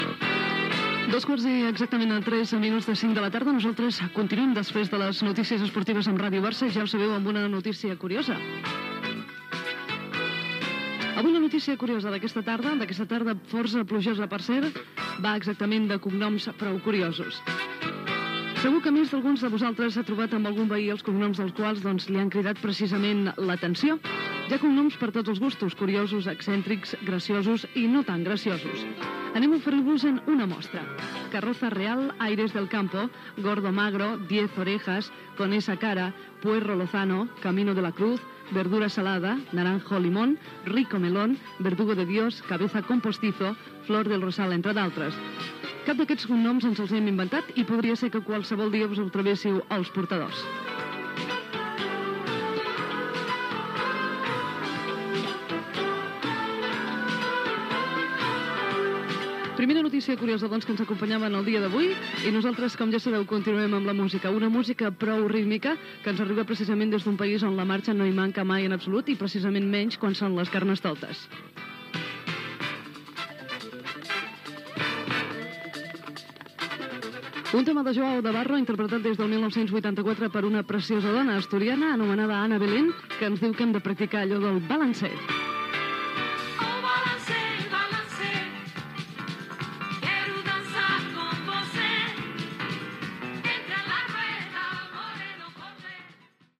Hora, notícia curiosa sobre els cognoms, tema musical
FM